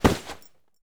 foley_combat_fight_grab_throw_09.wav